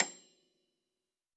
53r-pno29-A6.wav